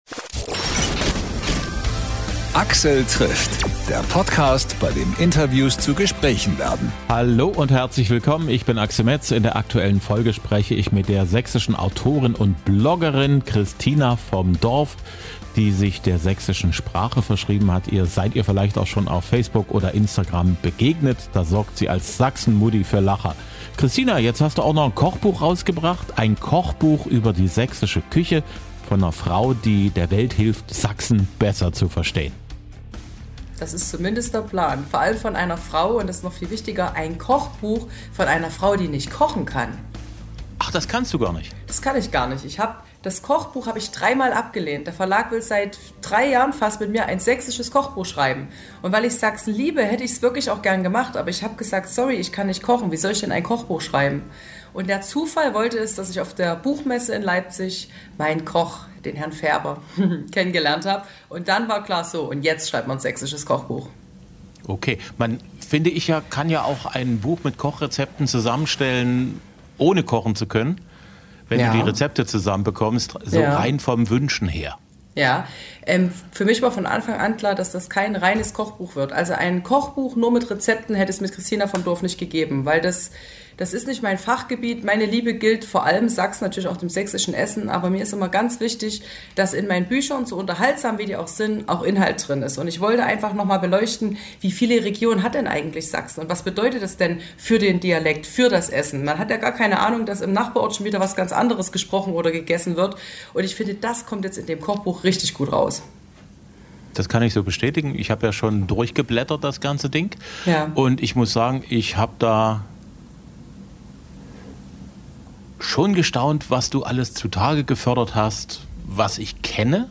InterviewPodcast